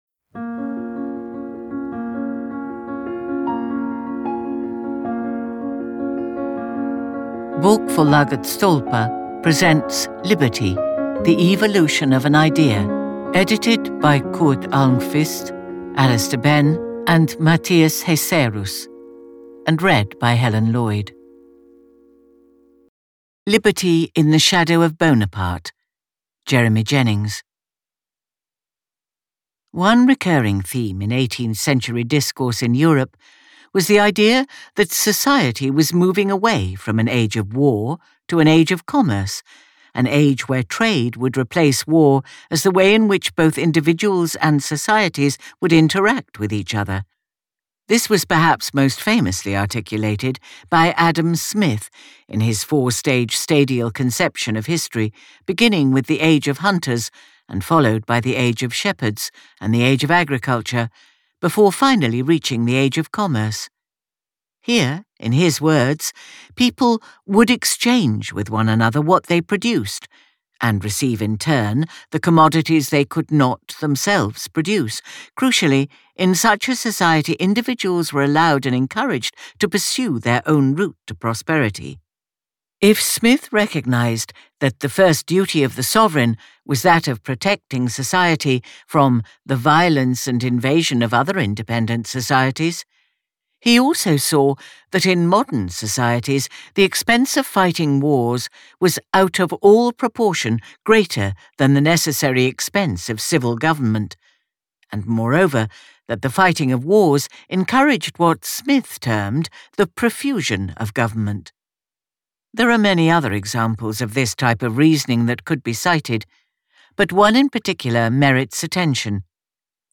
Weekly audio essays from leading experts.